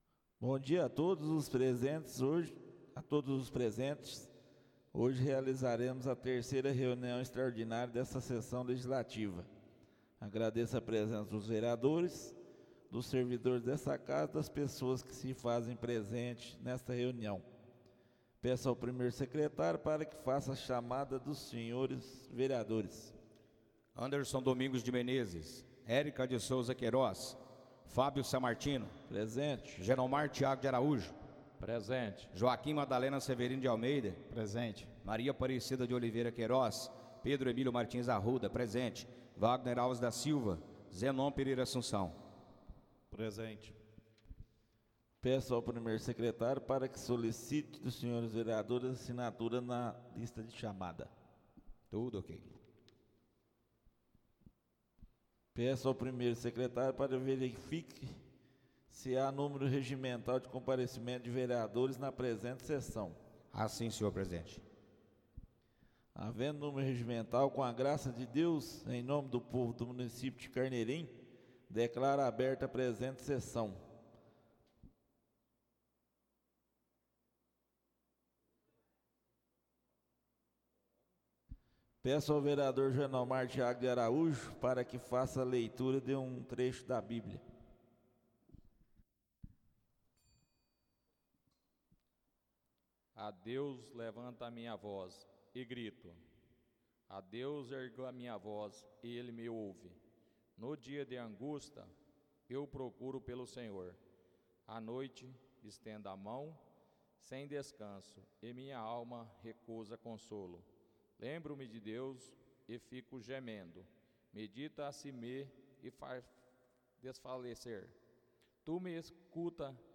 Áudio da 3.ª reunião extraordinária de 2023, realizada no dia 30 de Março de 2023, na sala de sessões da Câmara Municipal de Carneirinho, Estado de Minas Gerais.